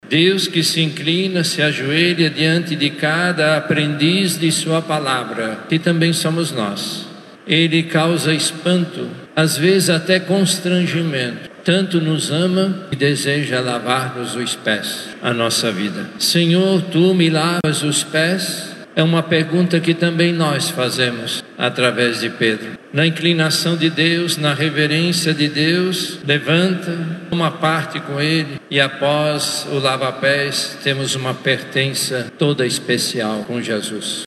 Em Manaus, a celebração foi presidida pelo Arcebispo, Cardeal Leonardo Steiner, na Catedral Metropolitana Nossa Senhora da Conceição.
Durante a homilia, o Cardeal recordou os passos de Jesus até aquele momento da Ceia, seu último encontro com os discípulos antes da Paixão.